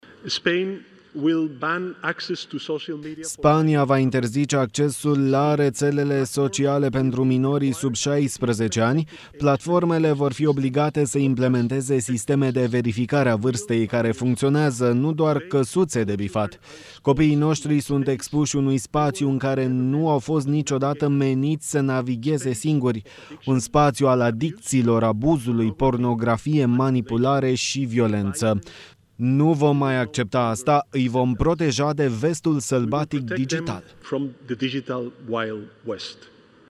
Premierul Spaniei, Pedro Sanchez, la summitul mondial al guvernelor din Dubai: „Copiii noștri sunt expuși unui spațiu în care nu au fost niciodată meniți să navigheze singuri”